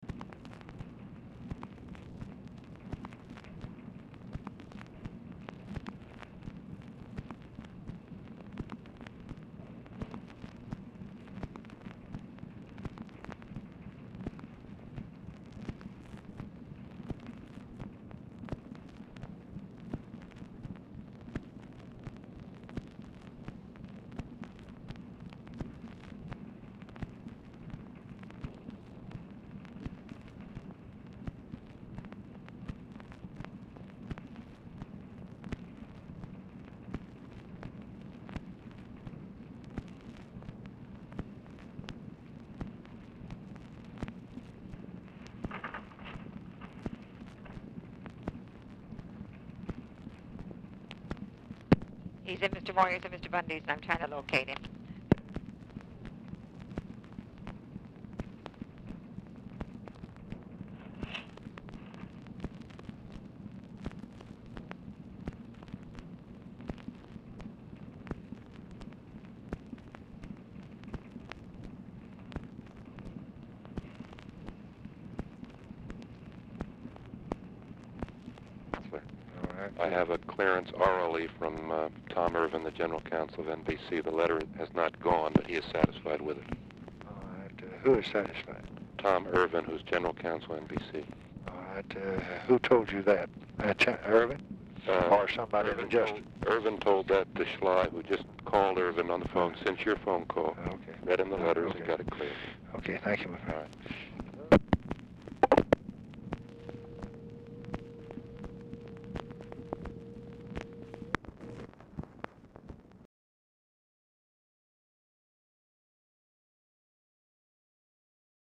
Telephone conversation
LBJ ON HOLD 1:15 WHILE OPERATOR TRIES TO GET KATZENBACH ON THE LINE
Dictation belt